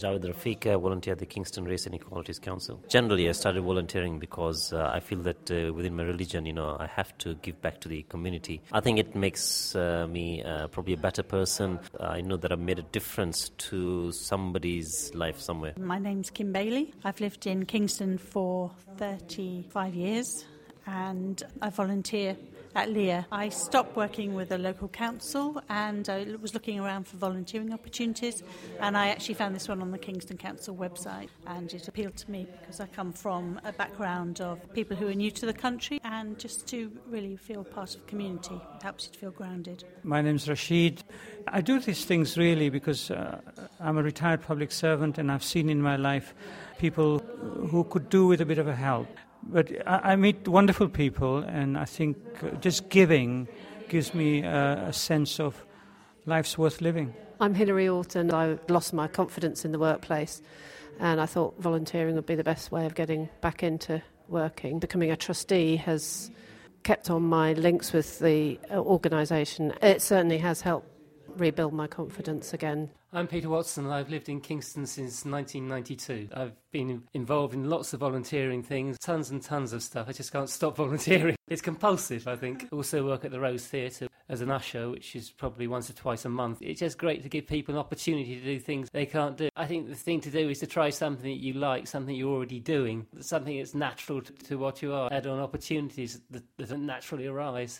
Volunteer interview medley